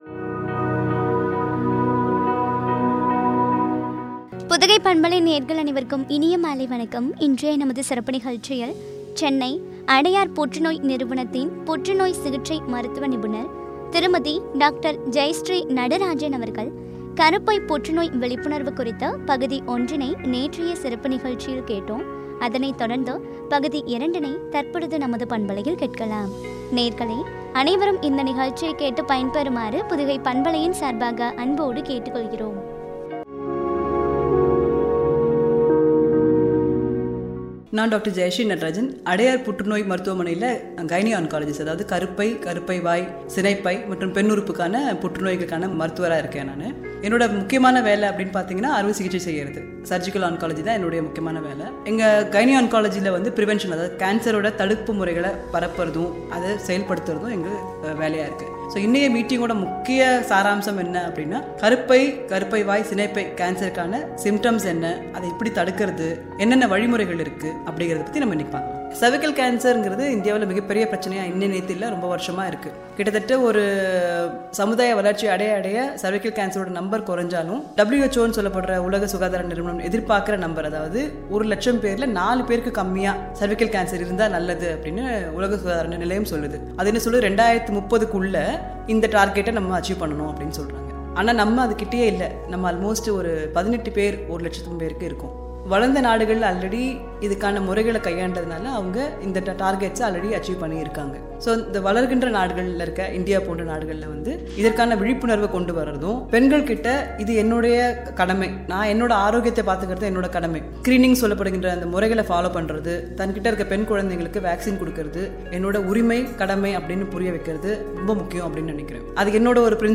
பற்றிய உரையாடல் .